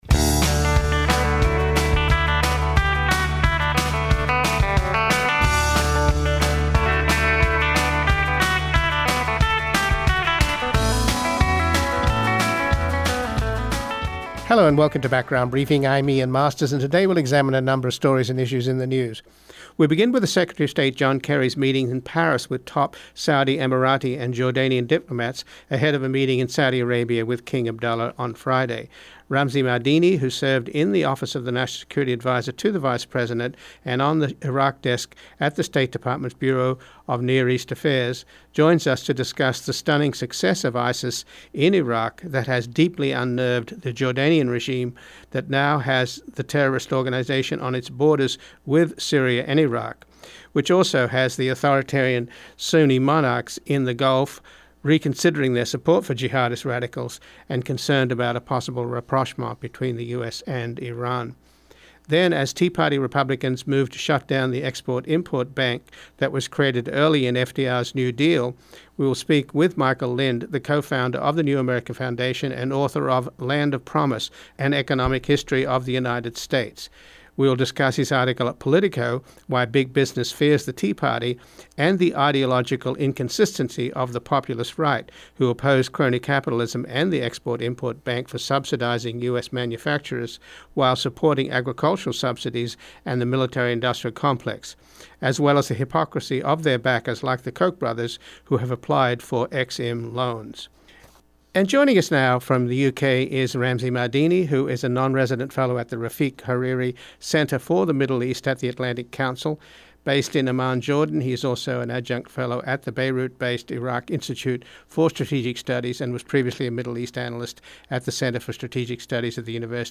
Full Program LISTEN TO FULL PROGRAM Part 1 We begin with the tense situation in Israel as three teenagers, missing for almost a month, were buried amid vows to make Hamas pay for their alleged murder. The former spokesman for Shimon Peres, Gideon Levy , an Israeli journalist and columnist for Ha’aritz, who has covered the Israeli occupation in the West Bank and Gaza for over 25 years, joins us to discuss the bombing of Gaza and the likelihood of military strikes targeting Hamas leaders as Prime Minister Netanyahu continues to insist that the murders of the teenagers are a consequence of the recent unity agreement between Fatah and Hamas.